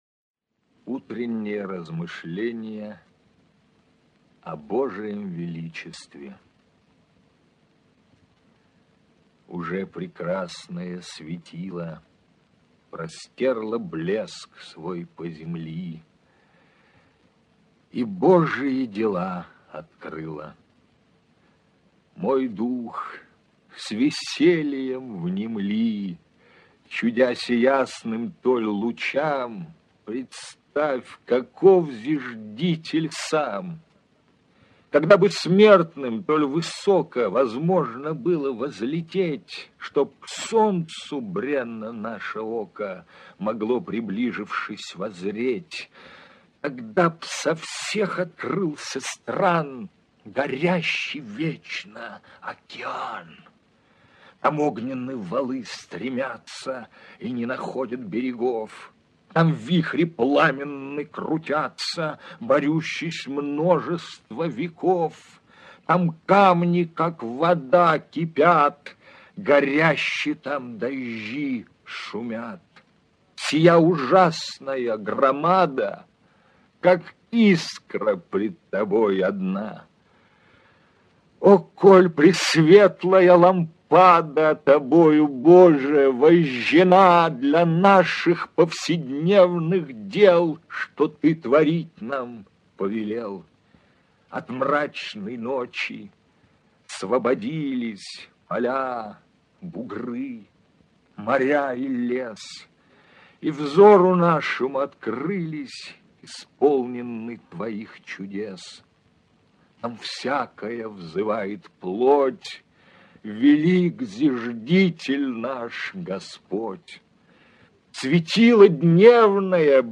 Прослушивание аудиозаписи оды «Утреннее размышление...» с сайта «Старое радио». Исп. Ю. Авшаров.